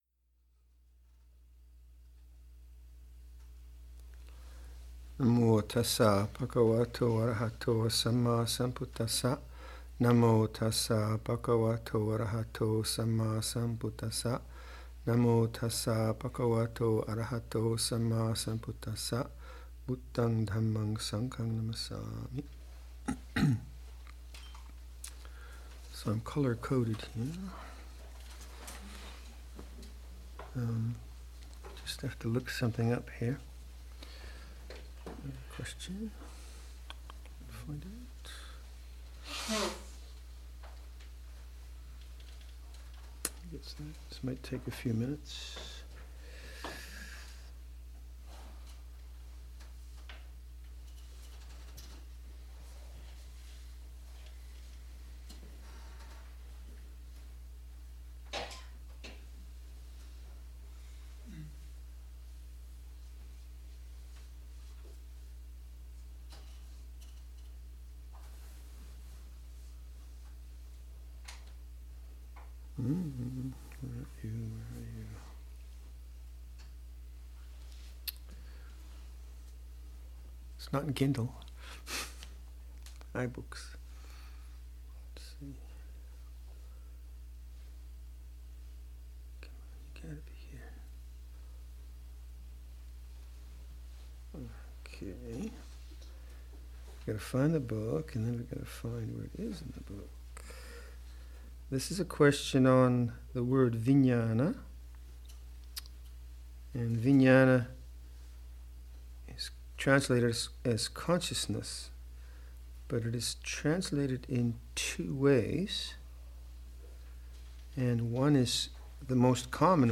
A Dhamma Talk given at the Arnprior Retreat, 2019